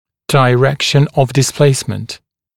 [dɪ’rekʃ(ə)n əv dɪs’pleɪsmənt] [daɪ-][ди’рэкш(э)н ов дис’плэйсмэнт] [дай-]направление смещения